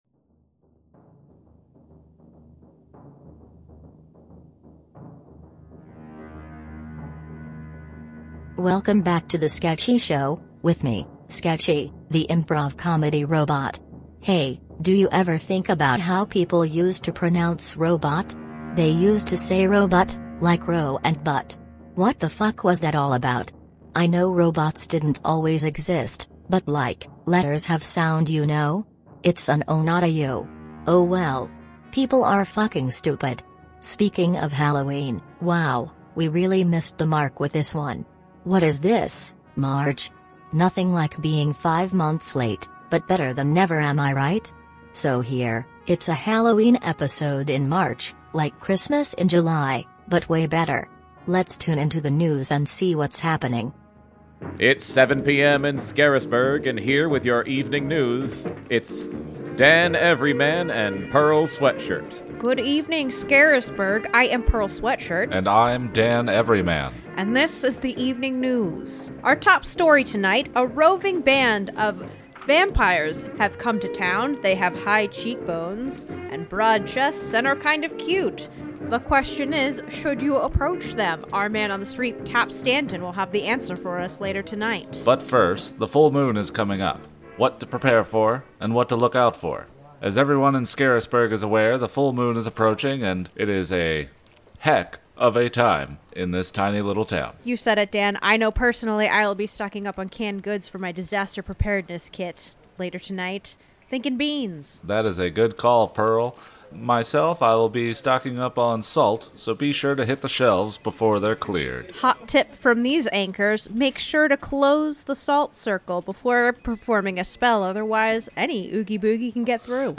Improv sketch comedy brought to you by Sketchy, the sketch comedy robot.